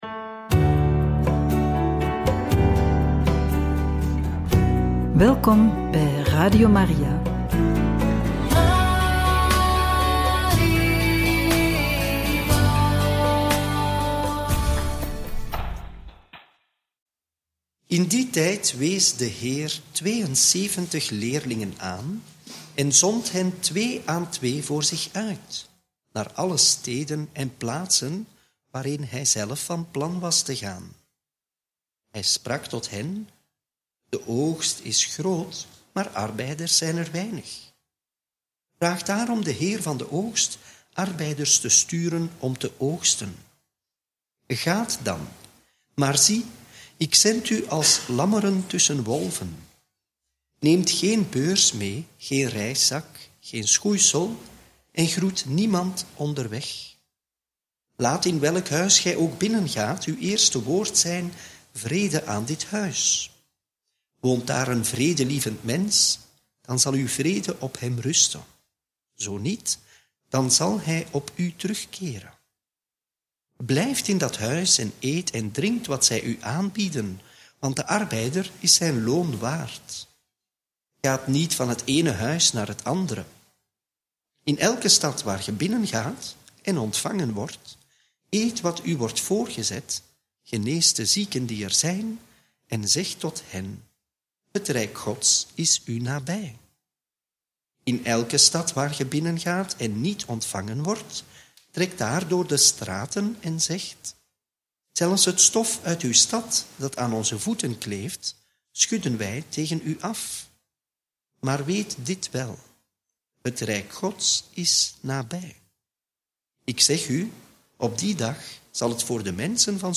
Homilie bij het Evangelie van donderdag 3 oktober 2024 – Lc 10, 1-12